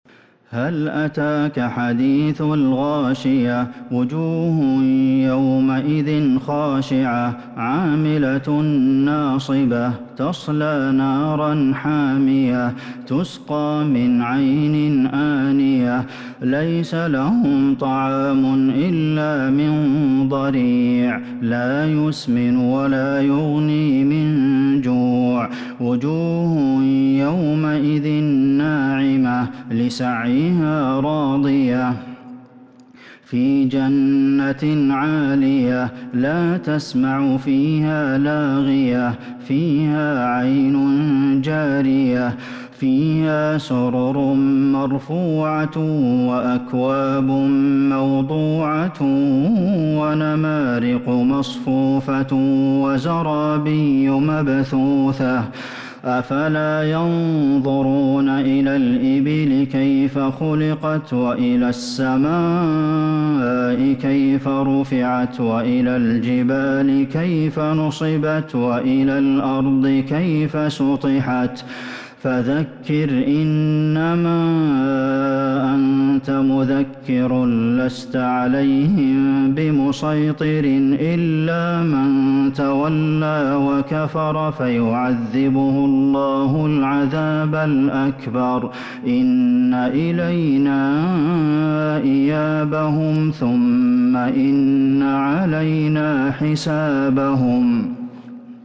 سورة الغاشية Surat Al-Ghashiyah من تراويح المسجد النبوي 1442هـ > مصحف تراويح الحرم النبوي عام 1442هـ > المصحف - تلاوات الحرمين